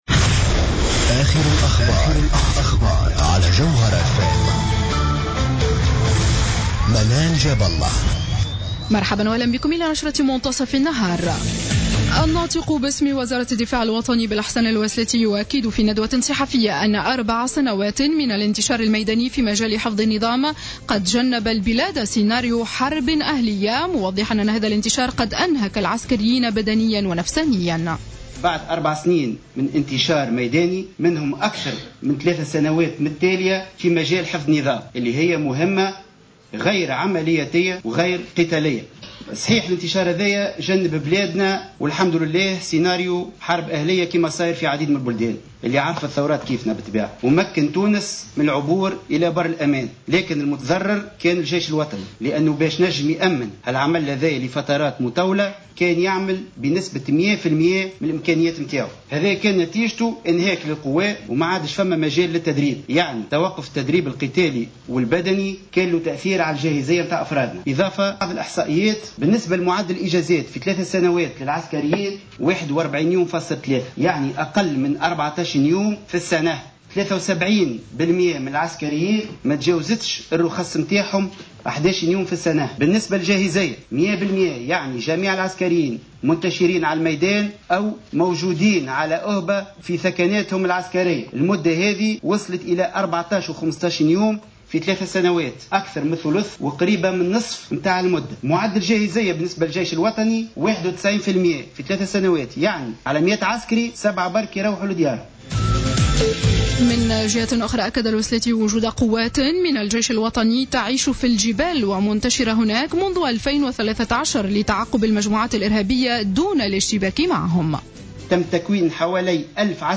نشرة أخبار منتصف النهار ليوم الثلاثاء 30-12-14